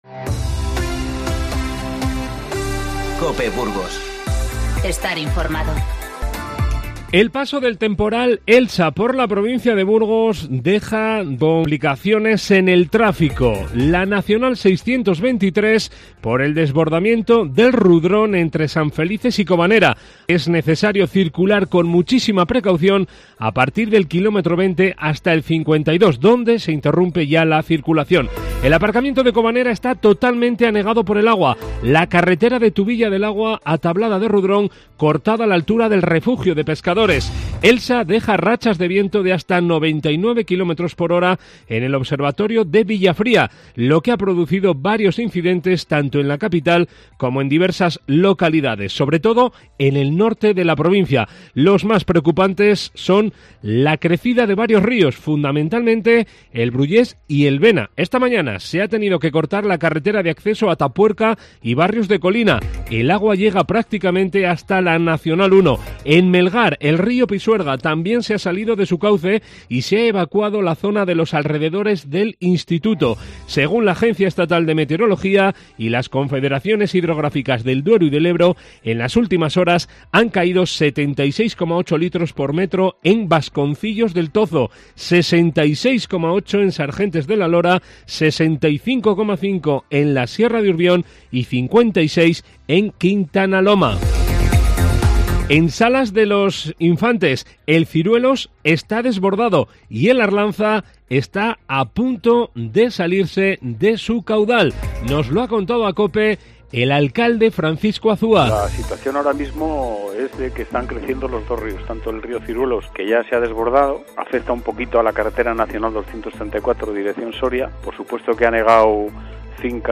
INFORMATIVO Mediodía 20-12-19